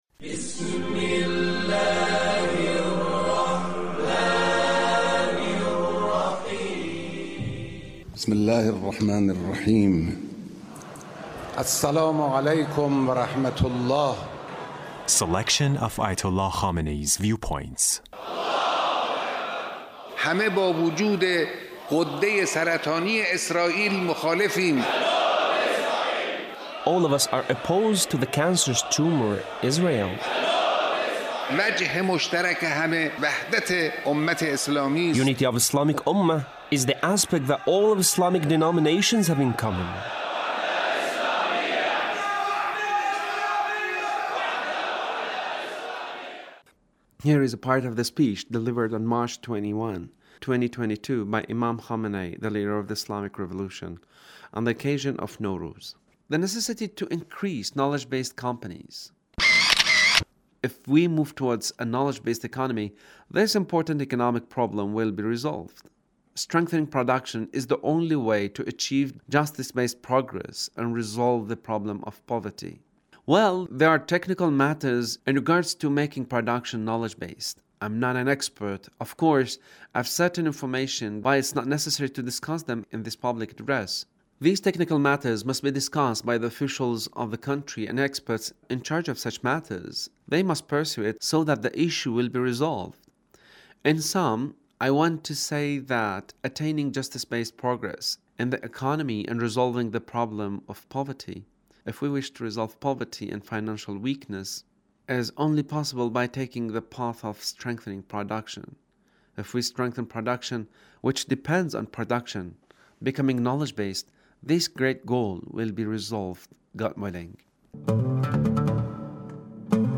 Leader's Speech (1665)